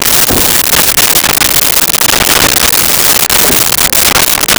Door Antique Open Close 03
Door Antique Open Close 03.wav